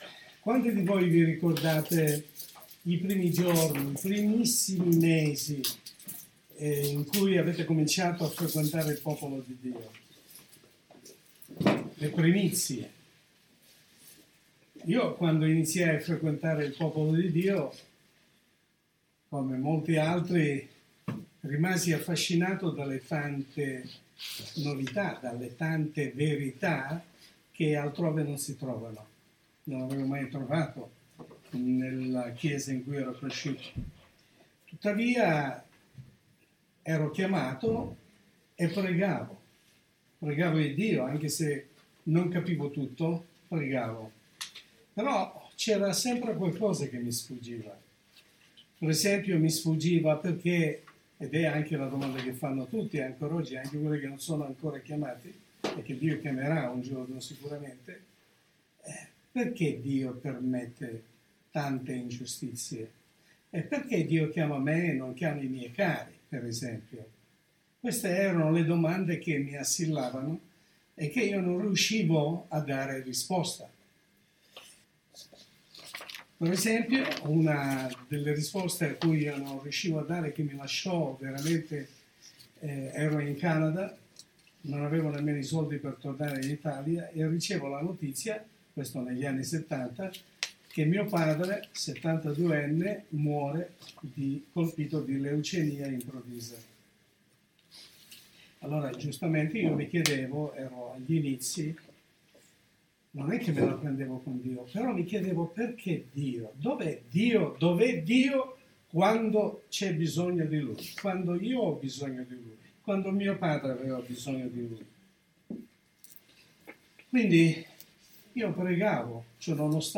Messaggio pastorale